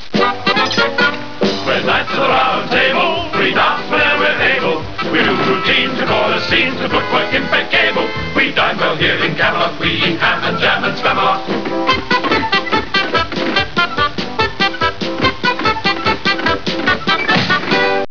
(in medieval hall)
KNIGHTS: (singing)